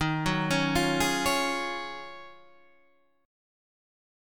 D#m11 chord